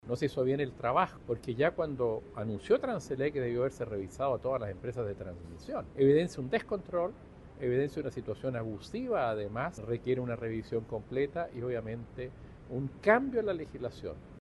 El diputado de la Federación Regionalista Verde Social, Jaime Mulet, hizo un llamado a los parlamentarios para respaldar la solicitud de una Comisión Investigadora de los sobre cobros y el sistema eléctrico.